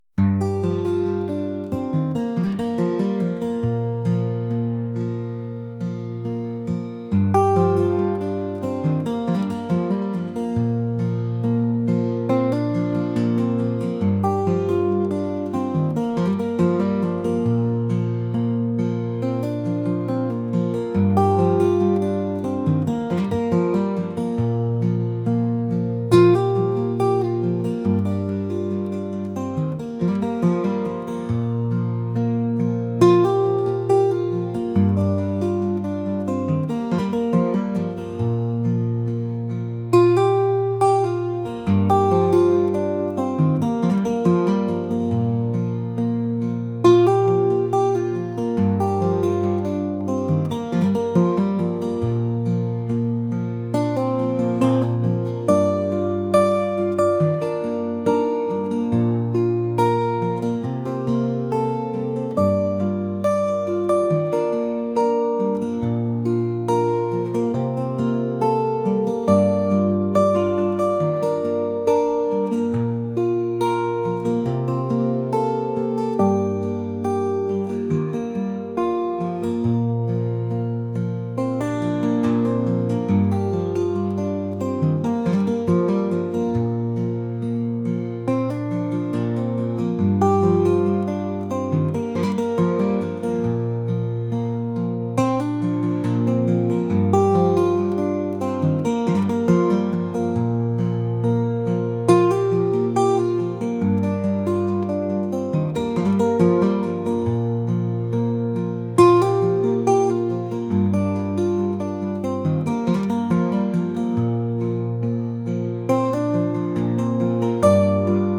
folk | indie | rock